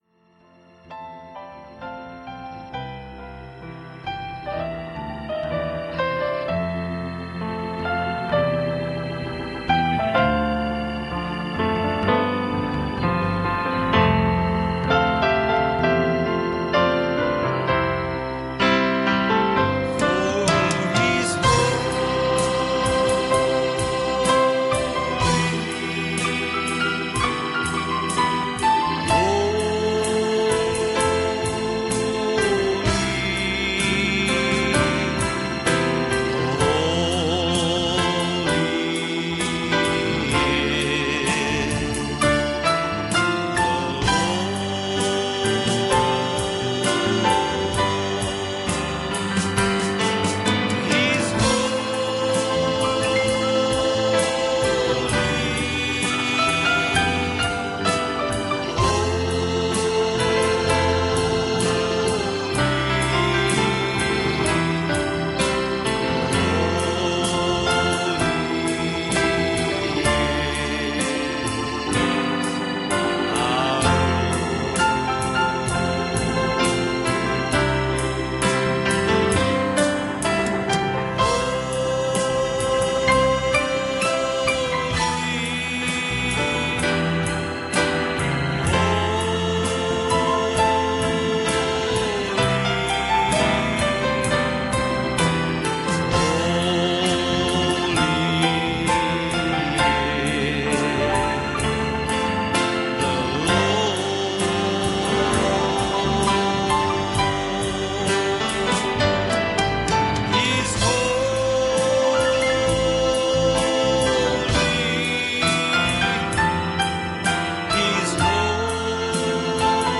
Identified Service Type: Sunday Morning Preacher